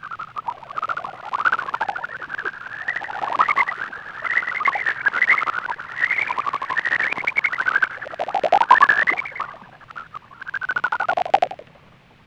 kereknyergupatkosdenever_barlangbejaratanal_ultrahang_ak00.12.WAV